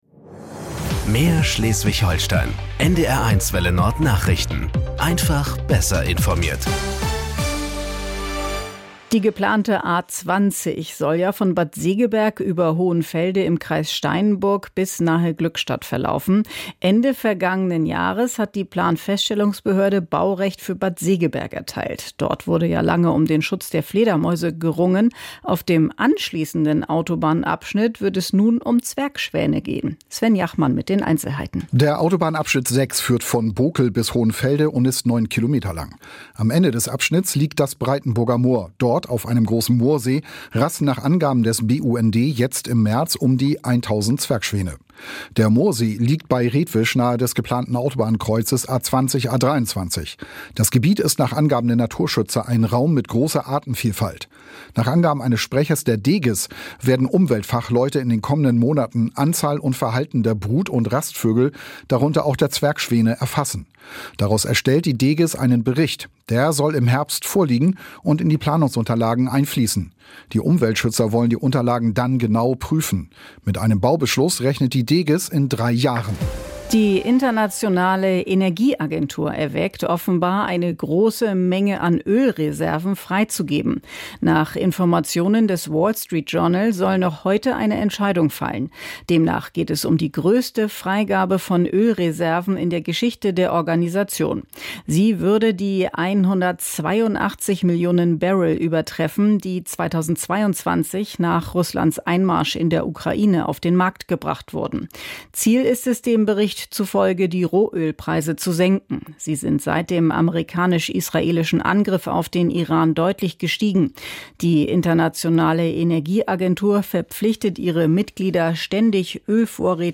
Nachrichten 09:00 Uhr - 11.03.2026